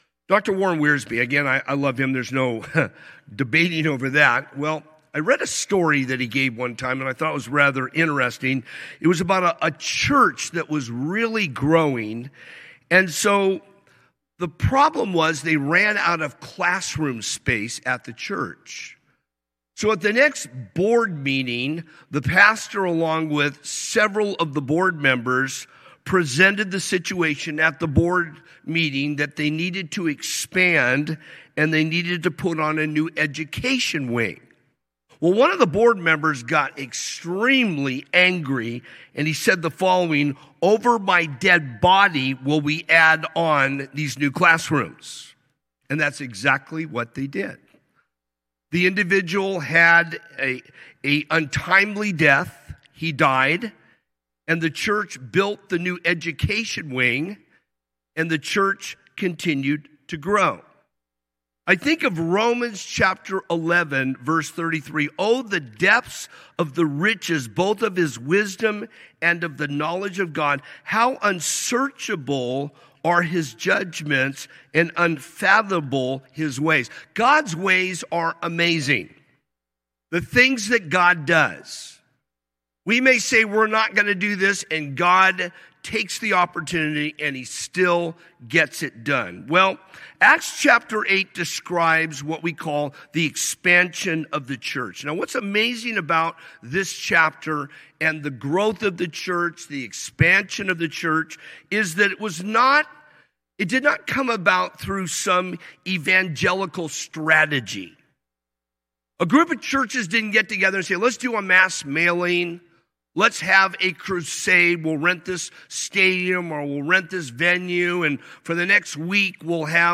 A message from the series "Wednesday - 19:00."